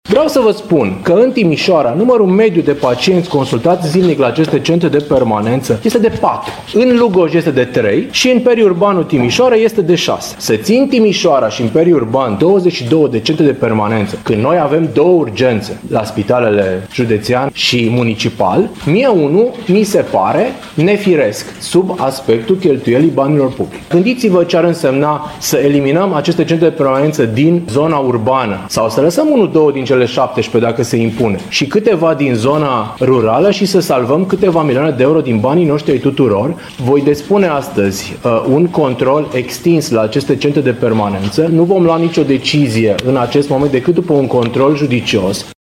Anual, se alocă aproape 7 milioane de euro pentru centrele de permanență din Timiș, indiferent de numărul pacienților care se adresează acestor medici, spune Mihai Ritivoiu